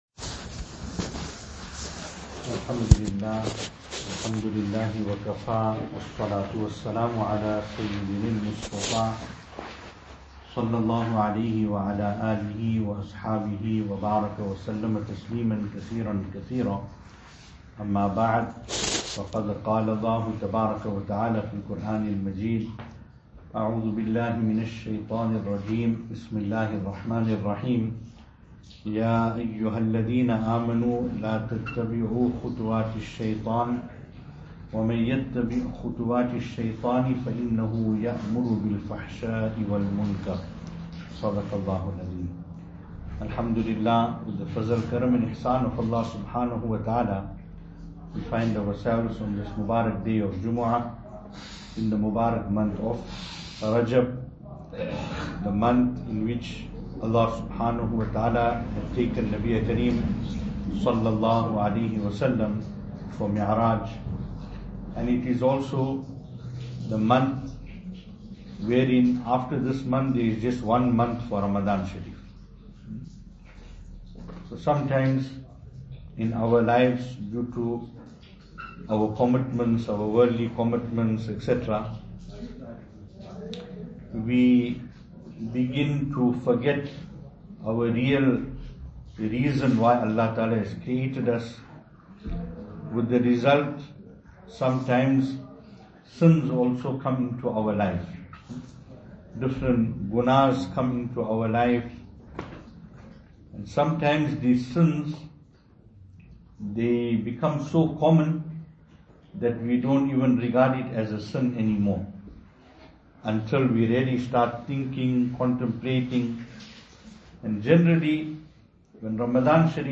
Venue: Cool Air Musallah Service Type: Jumu'ah